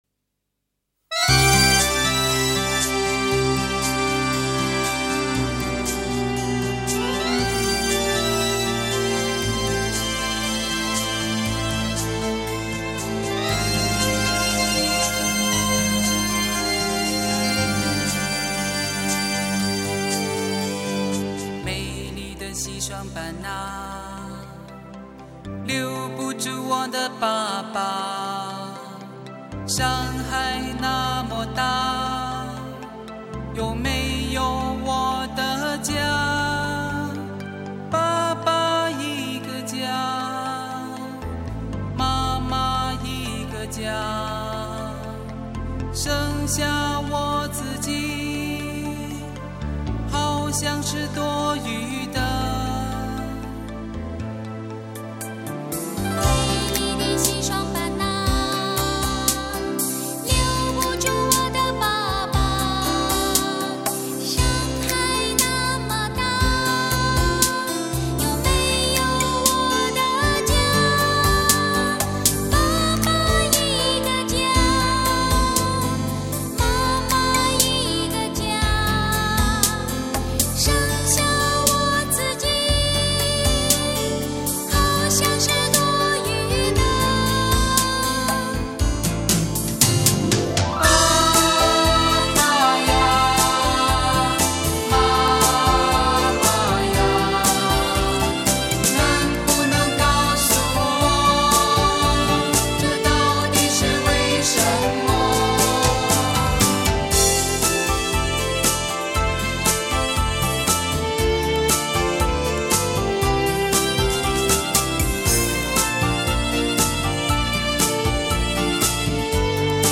这首令听者倍觉酸楚的歌唱出了一个被父母抛弃的儿童的伤感心情。